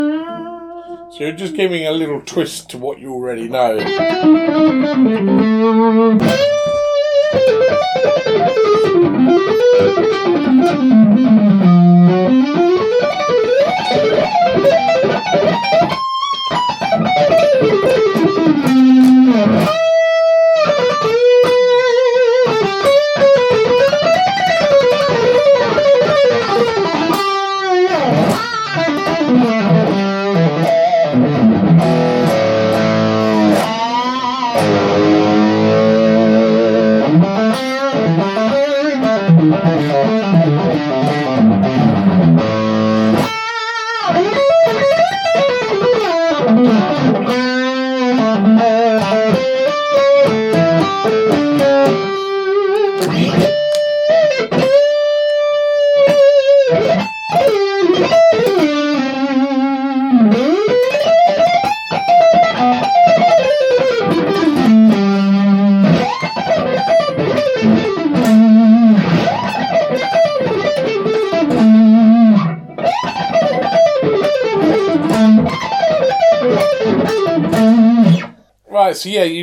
Punctuated by demonstrations and improvisations throughout over backing tracks from YouTube, we hope that you gain some licks, ideas and ways of looking at blues improvisation which are useful and informative.